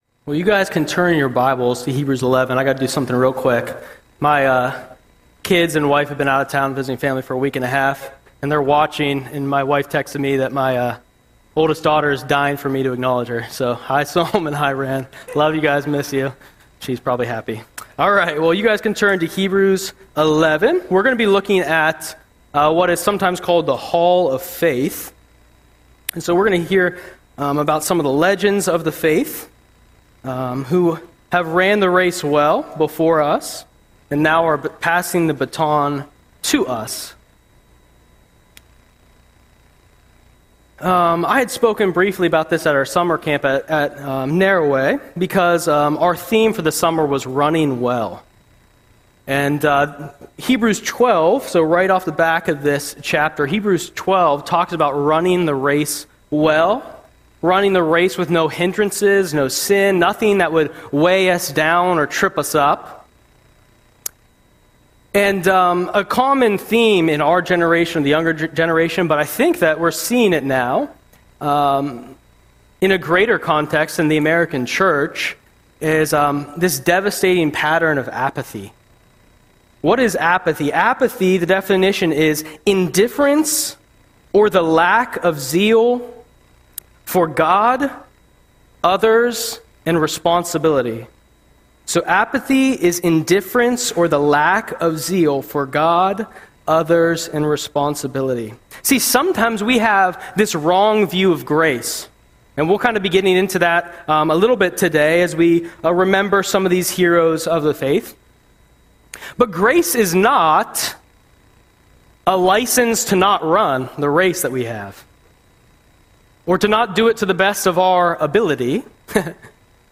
Audio Sermon - July 13, 2025